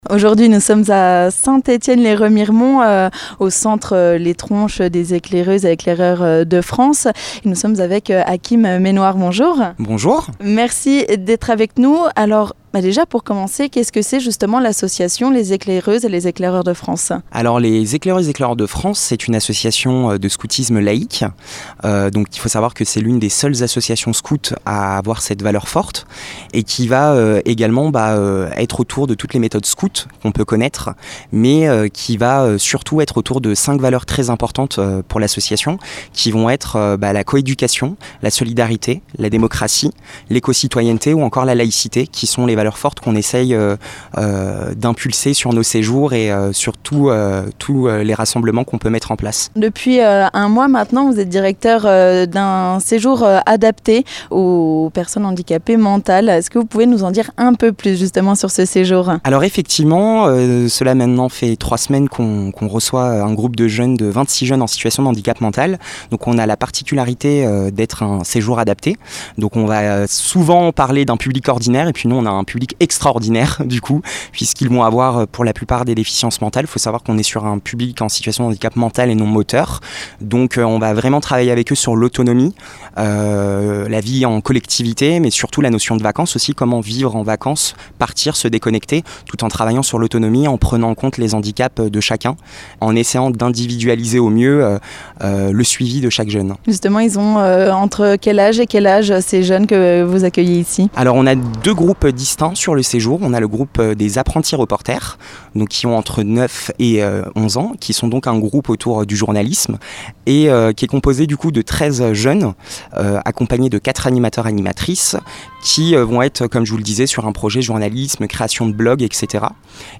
Rencontre avec ces jeunes